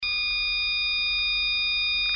ZUMBADOR - SONIDO CONTINUO
Zumbador electrónico - sonido contínuo
Continuo